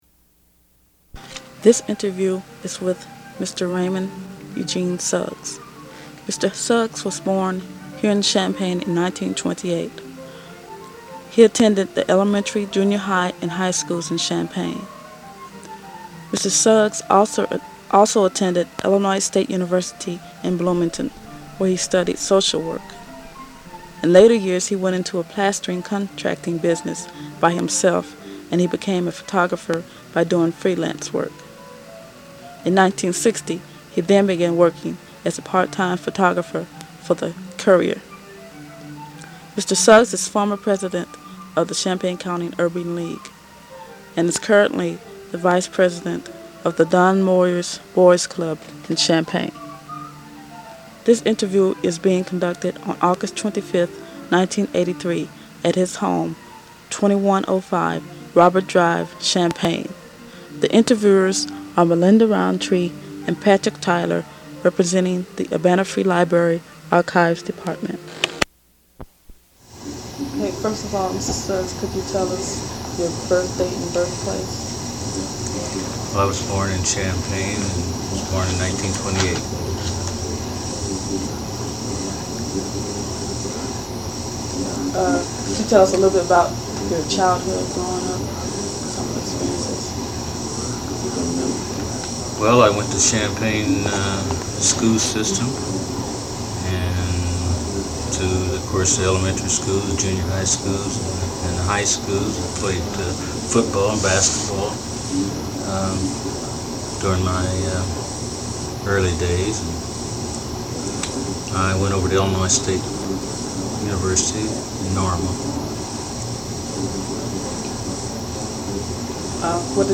Oral History